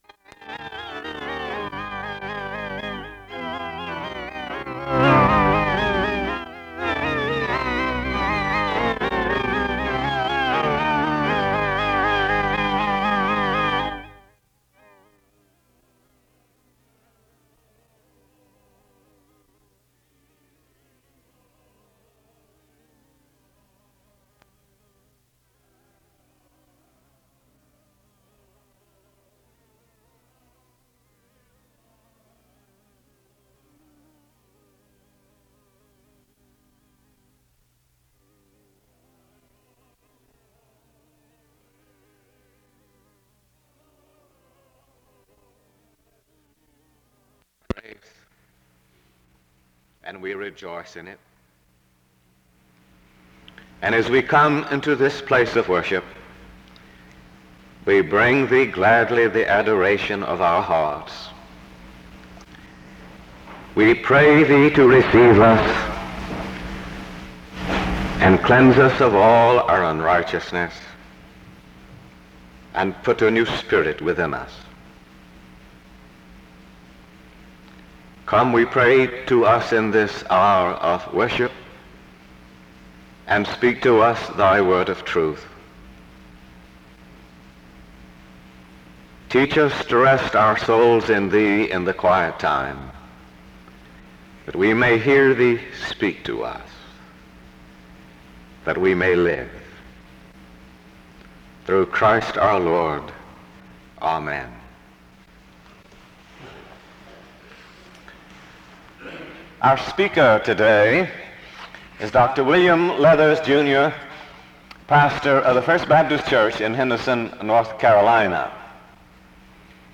The service opens with music from 0:00-0:15. A prayer is offered from 0:49-1:45. An introduction to the speaker is given from 1:49-3:46.
SEBTS Chapel and Special Event Recordings SEBTS Chapel and Special Event Recordings